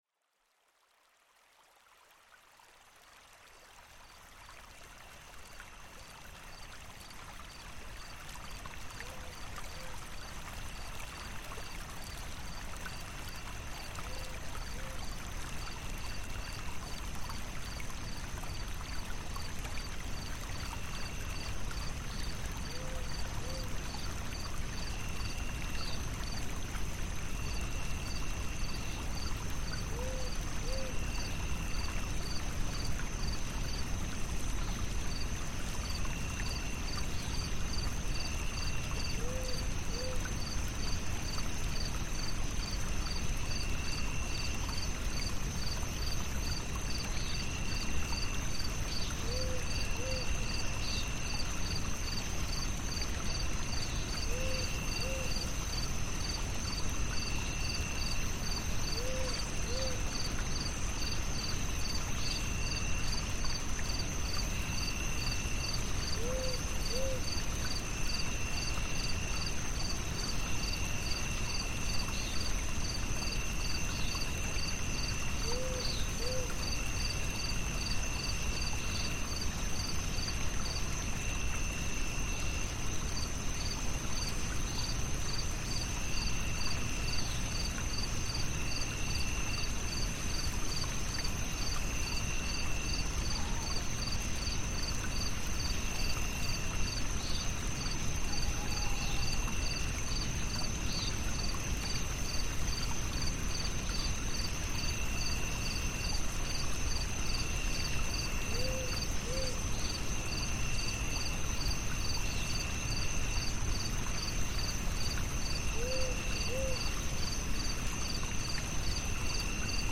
Each episode of Send Me to Sleep features soothing soundscapes and calming melodies, expertly crafted to melt away the day's tension and invite a peaceful night's rest. Imagine the gentle hum of a distant thunderstorm, the serene flow of a mountain stream, or the soft rustle of leaves in a midnight breeze—sounds that naturally lull you into deep relaxation.